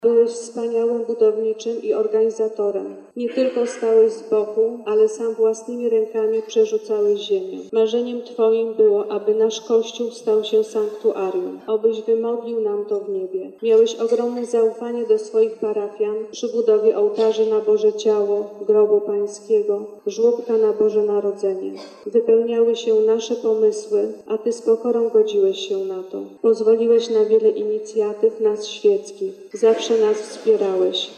Parafianie dziękowali zmarłemu proboszczowi za jego otwartość, oddanie i wspólną pracę na rzecz całej wspólnoty.
14-12-Parafianie.mp3